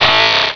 pokeemerald / sound / direct_sound_samples / cries / ninjask.aif